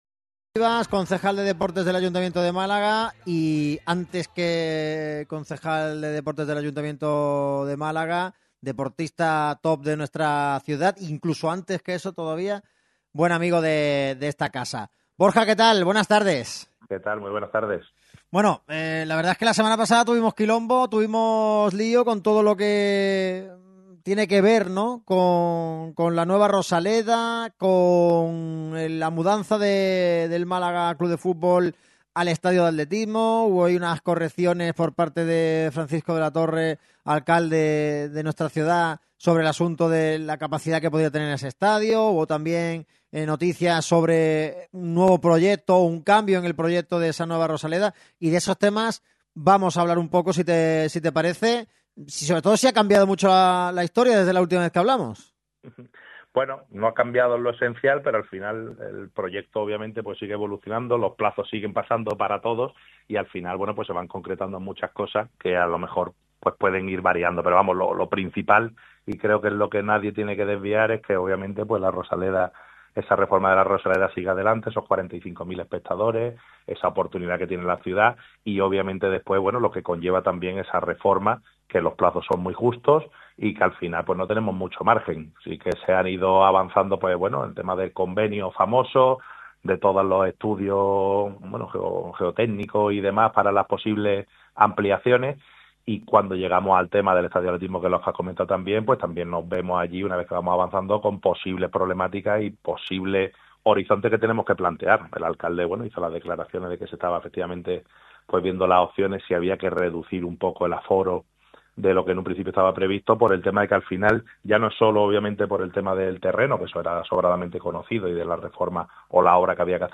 El concejal de Deportes del Ayuntamiento de Málaga toma el turno de palabra. Lo ha hecho en el micrófono rojo de Radio MARCA Málaga.